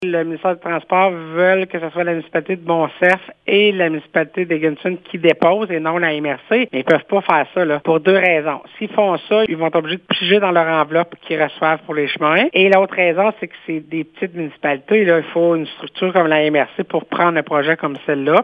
Le ministère souhaitait que les Municipalités de Montcerf-Lytton et d’Egan Sud déposent le projet. Cette proposition a été rejetée par la MRC et la préfète explique pourquoi :